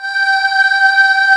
VOX XCHOIR04.wav